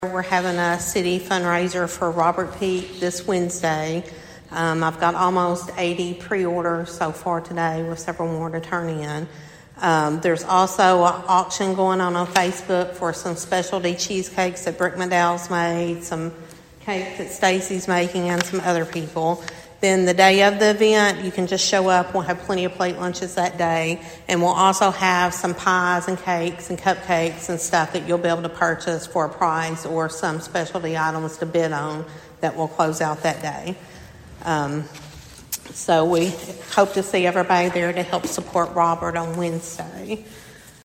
At the City Council meeting on Monday night